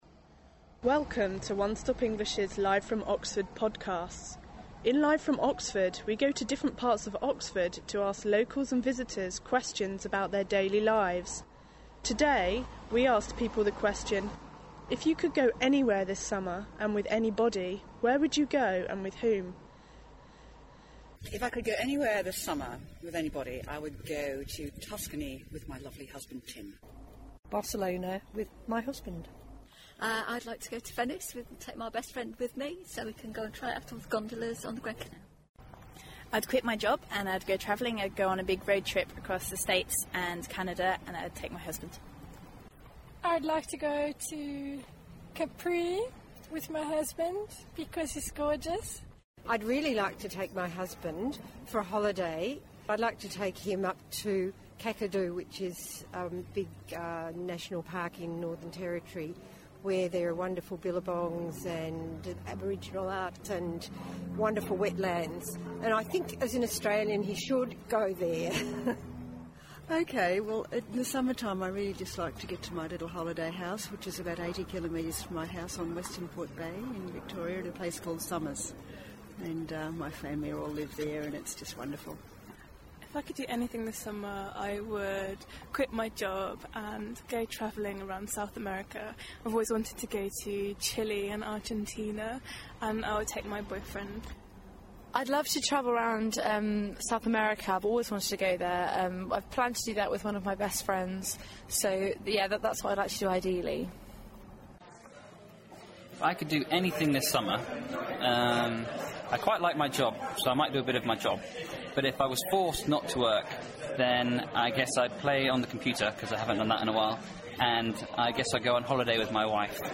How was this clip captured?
This month the onestopenglish reporters are in Oxford, asking locals and visitors questions about their daily lives. We walked around the university and asked people about their ideal summer plans.